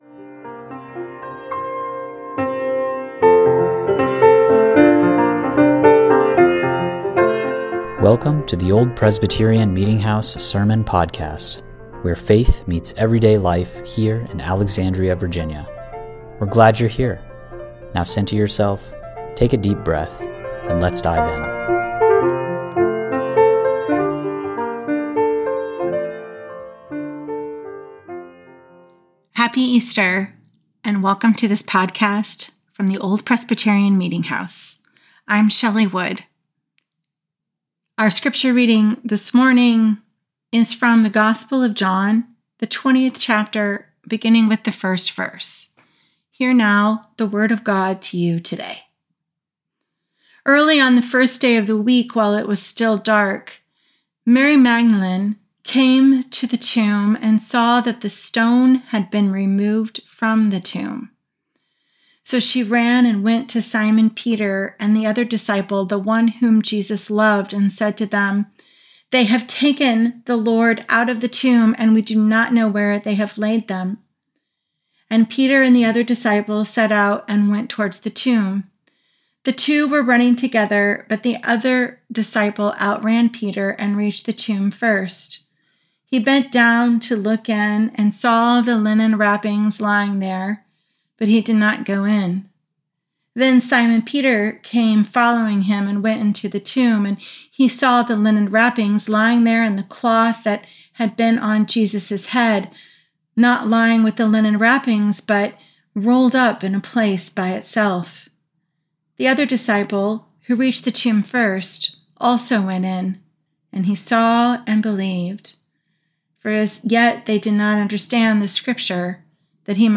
OPMH Sermon Podcast Assurance